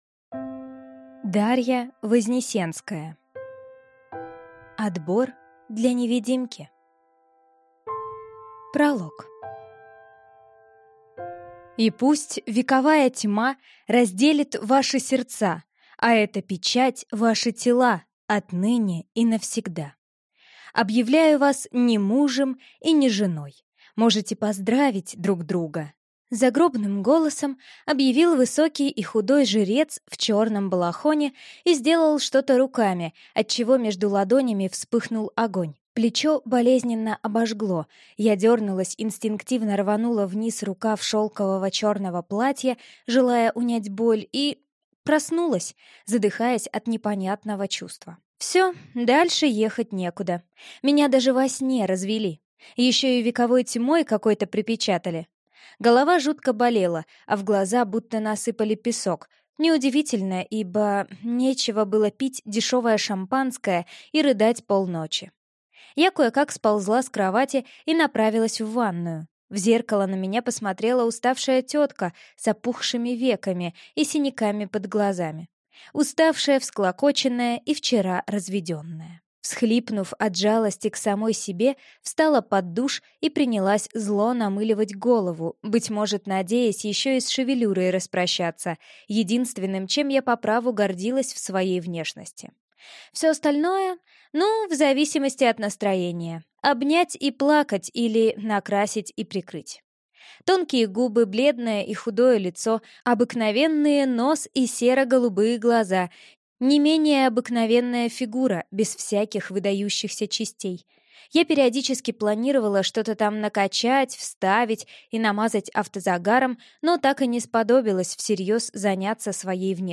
Аудиокнига Отбор для невидимки | Библиотека аудиокниг